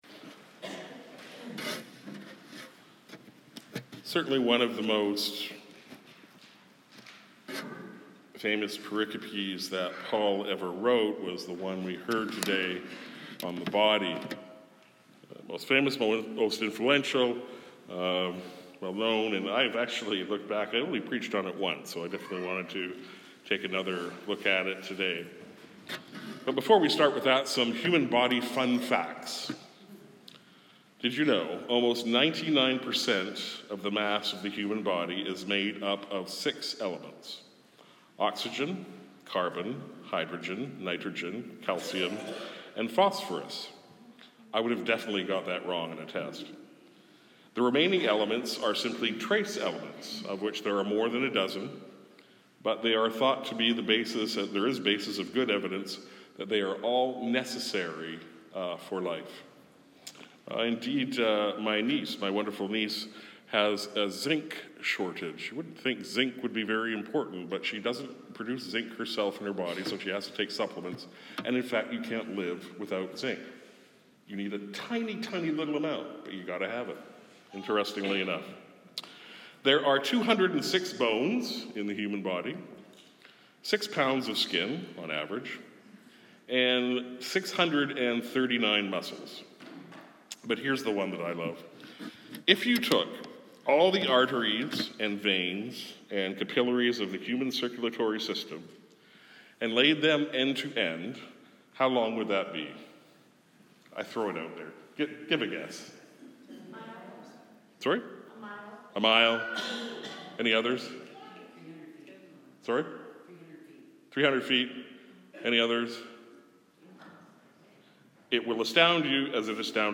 Sermons | St. John the Evangelist